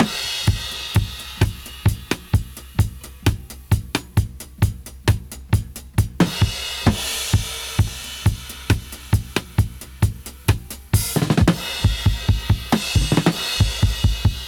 131-DRY-01.wav